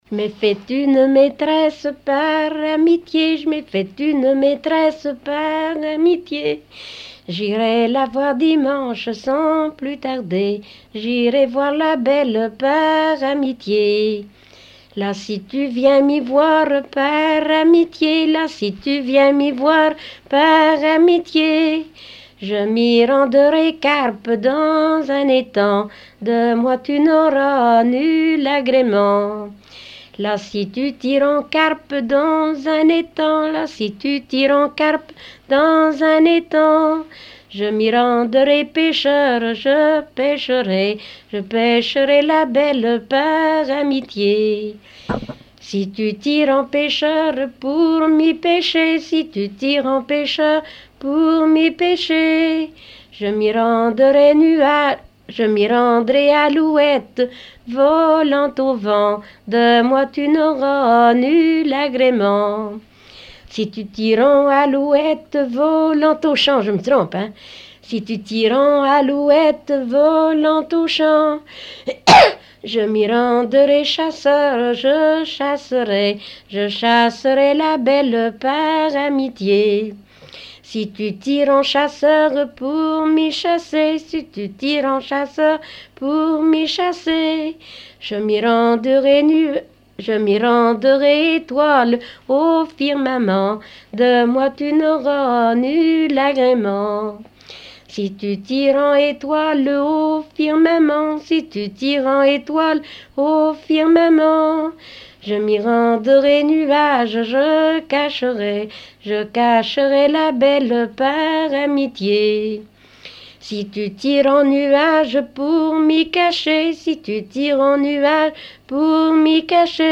Mémoires et Patrimoines vivants - RaddO est une base de données d'archives iconographiques et sonores.
Genre énumérative
Catégorie Pièce musicale inédite